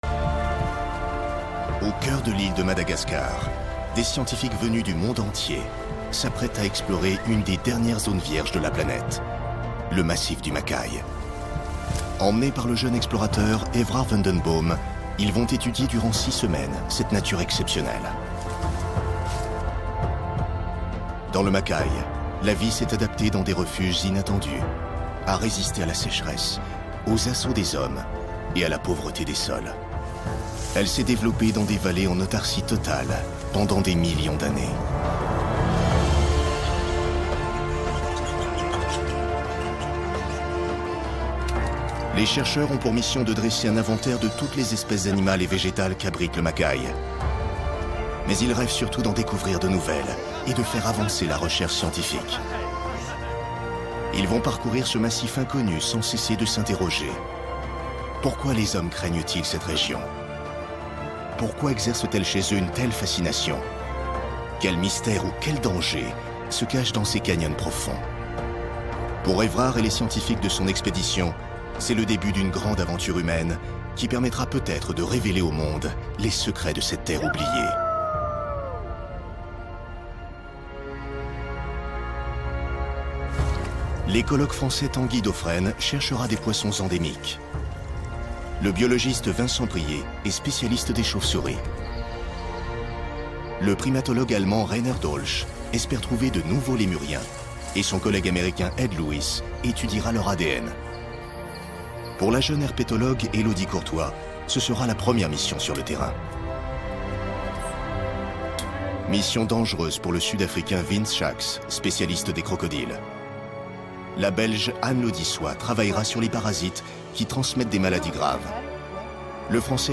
Prestation voix-off virile et convaincante pour "Makay, les aventuriers du monde perdu" - Intro
Voix "aventurier" - dynamique et solennelle.
Documentaire sur le massif du Makay.
Dans la réalisation de ce projet, j’ai utilisé une tonalité grave pour renforcer l’aspect viril du personnage et pour donner plus de poids à l’aventure.